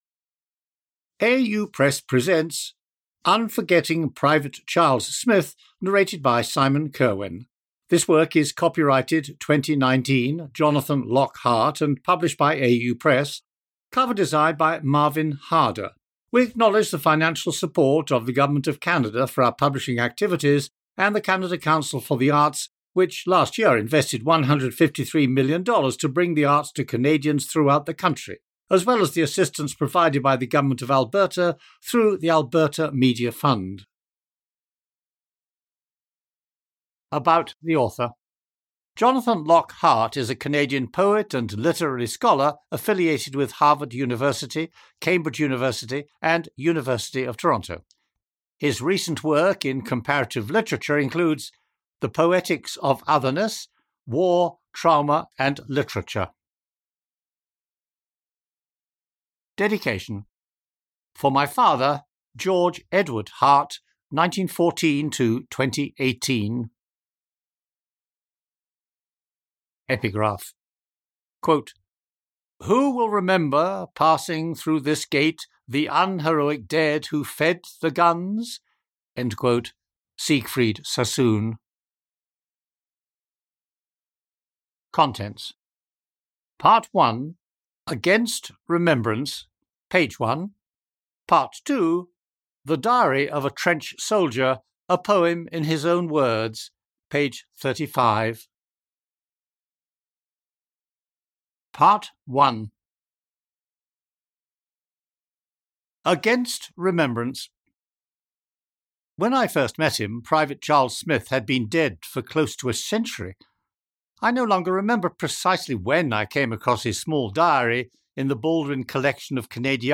Unforgetting Private Charles Smith (Audio Book)